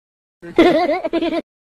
Funny Laugh Sound Effect Free Download
Funny Laugh